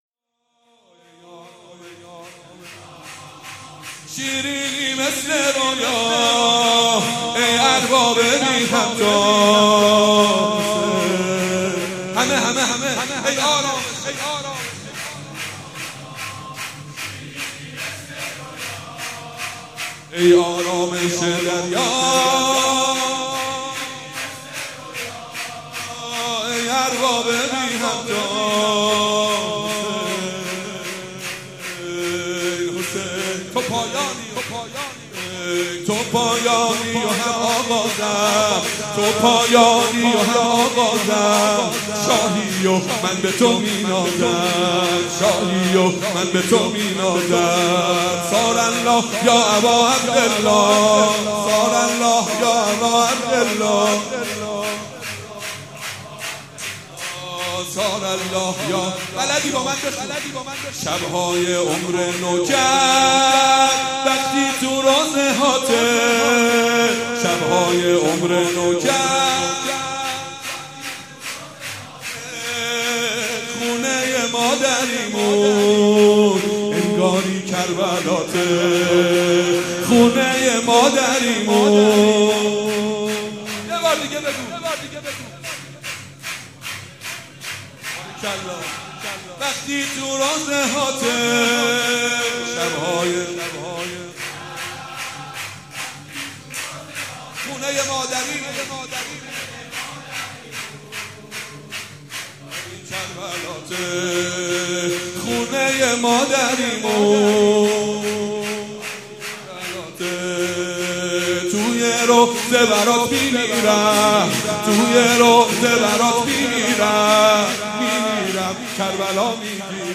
سرود مداحی